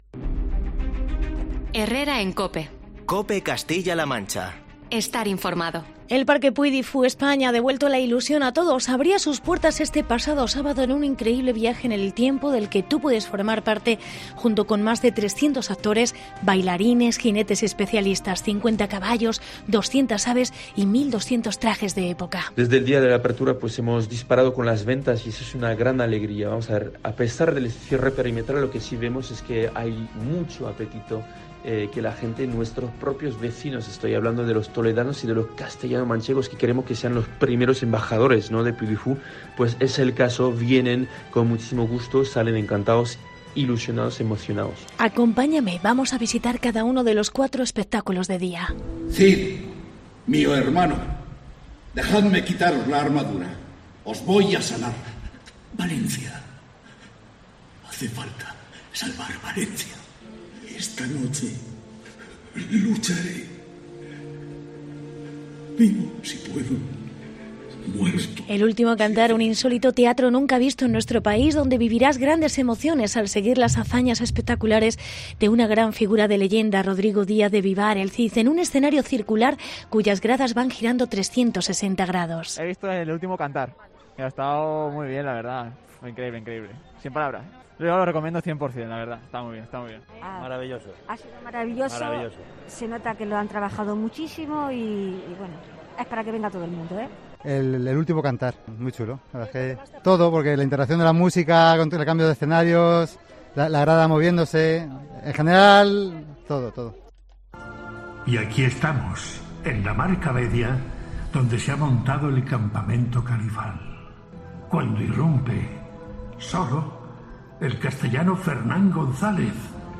Reportaje Puy Du Fou. Día de la inauguración